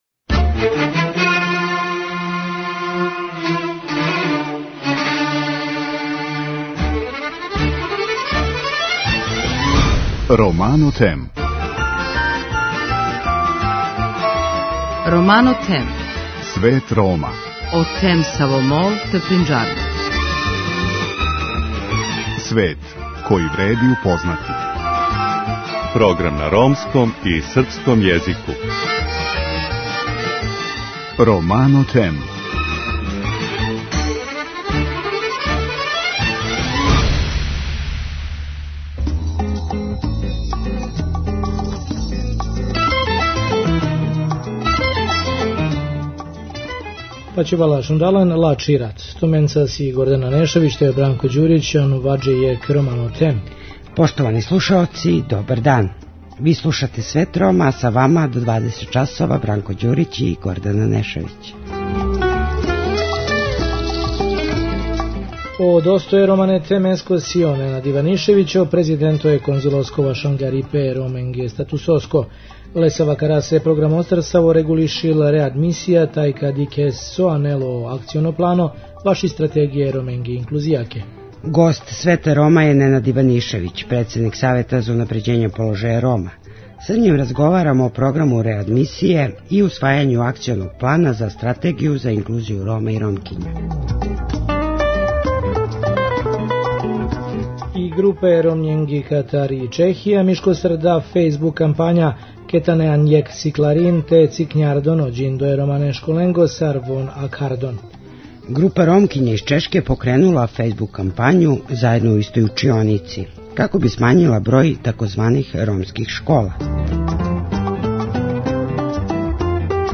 Гост Света Рома је Ненад Иванишевић председник Савета за унапређење положаја Рома. Са њим разговарамо о програму реадмисије и усвајању Акционог плана за Стратегију за инклузију Рома и Ромкиња.